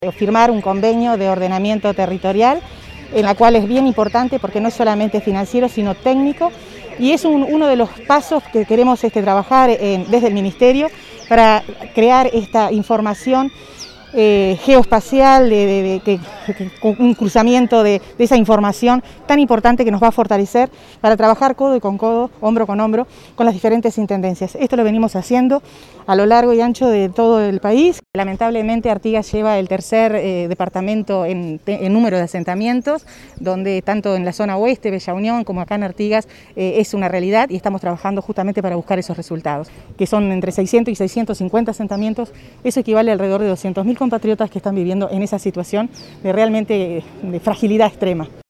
Declaraciones a la prensa de la ministra de Vivienda, Irene Moreira